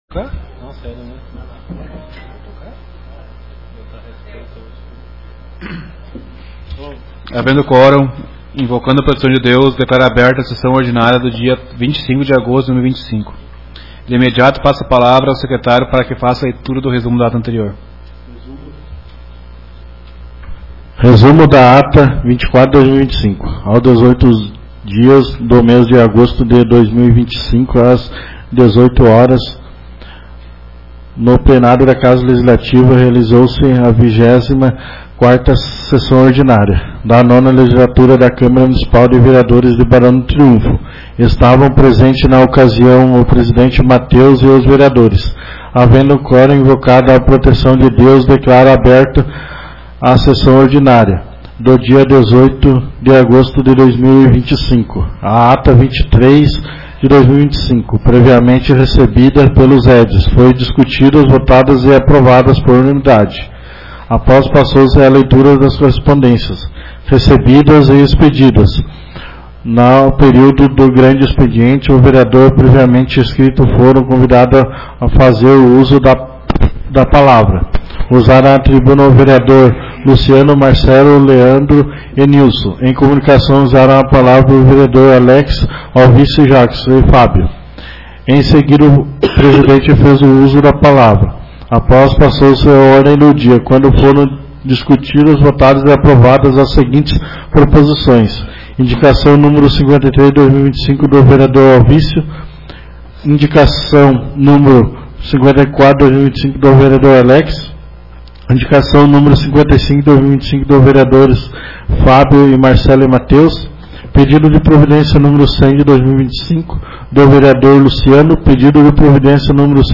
Áudio das Sessões